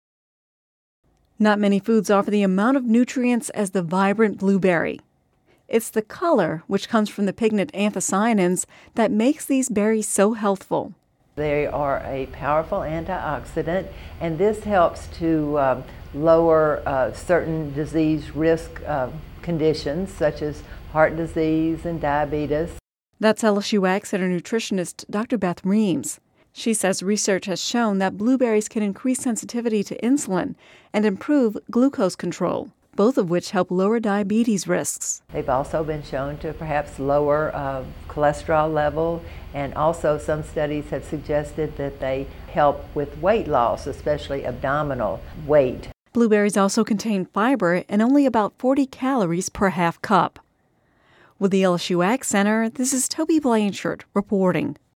(Radio News 6/21/10) Not many foods offer the amount of nutrients as the vibrant blueberry. It’s the color, which comes from the pigment anthocyanins, that makes these berries so healthful.